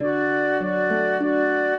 flute-harp
minuet0-11.wav